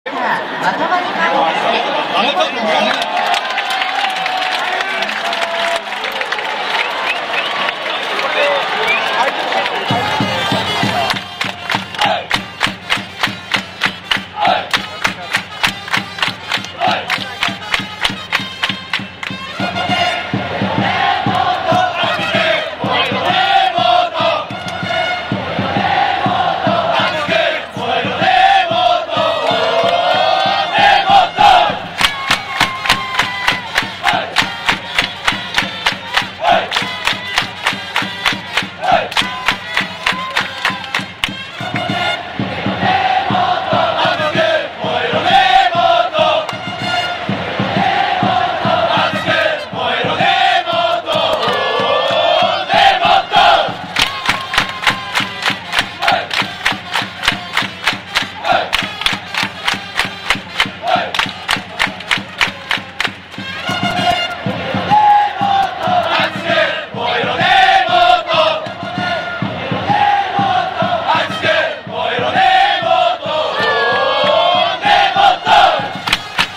sound of stadium
このコーナーは管理人が隠れながら（？）球場で録音した音を公開していくコーナーです。